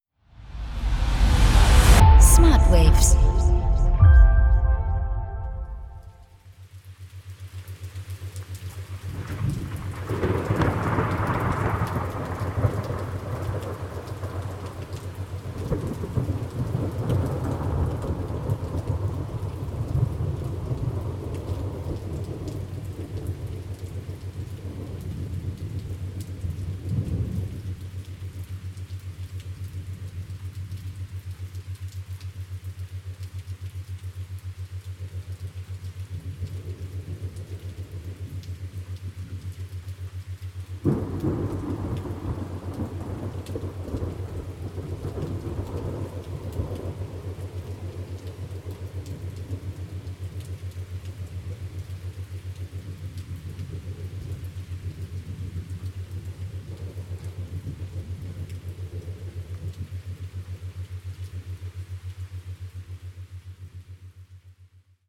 RAIN & THUNDER
Rain_Thunder_snip.mp3